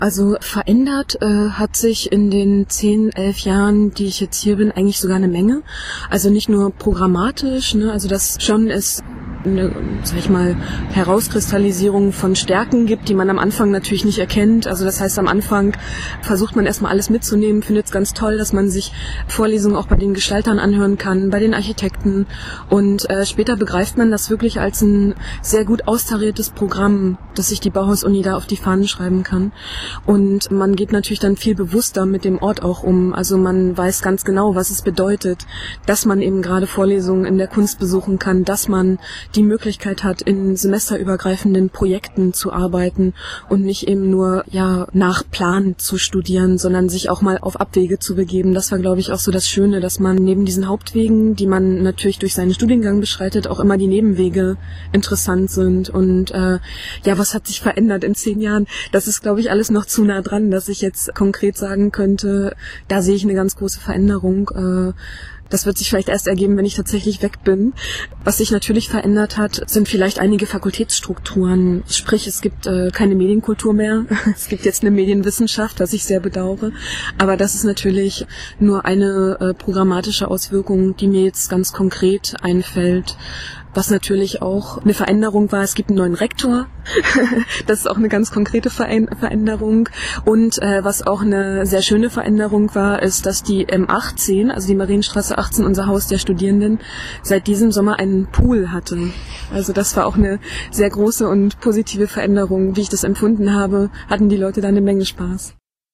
an einem herbstlichen Tag im Ilmpark zahlreiche Geschichten aus ihrer Zeit in Weimar erzählt. Ihre Anekdoten von angehenden Wissenschaftlern, studentischem Engagement und viel Musik können Sie hier anhören.